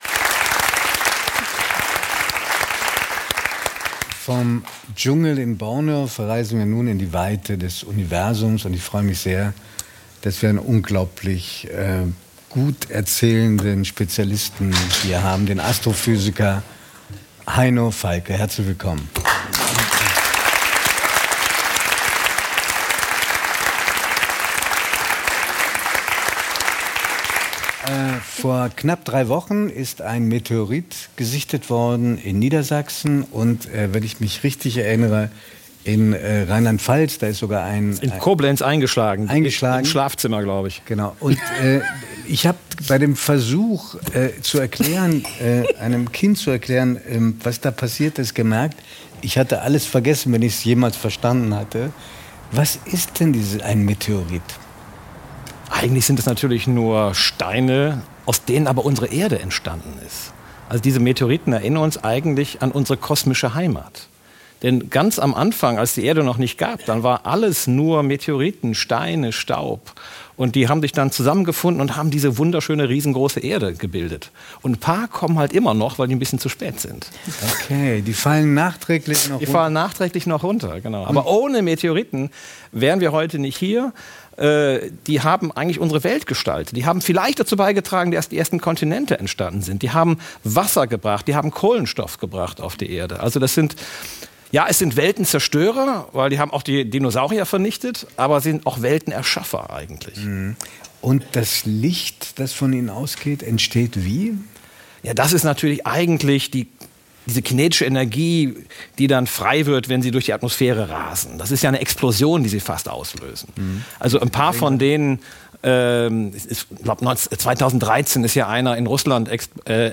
Heino Falcke – Astrophysiker ~ 3nach9 – Der Talk mit Judith Rakers und Giovanni di Lorenzo Podcast